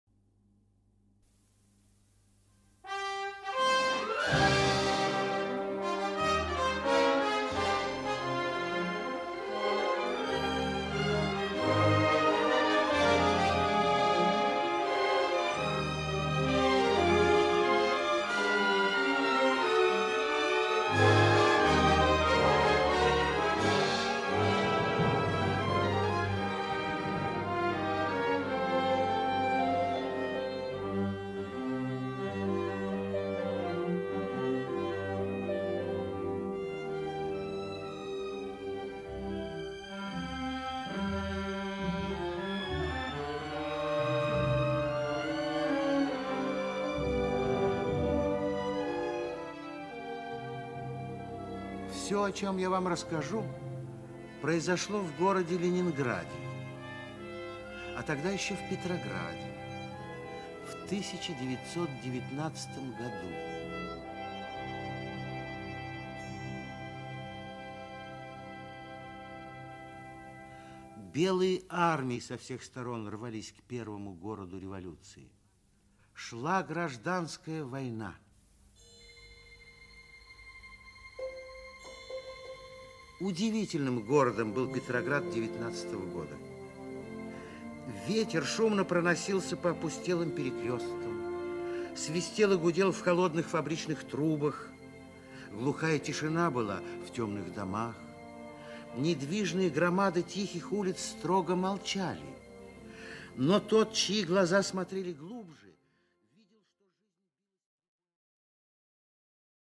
Аудиокнига Пулковский меридиан. Часть 1. «Два друга» | Библиотека аудиокниг
«Два друга» Автор Лев Успенский Читает аудиокнигу Актерский коллектив.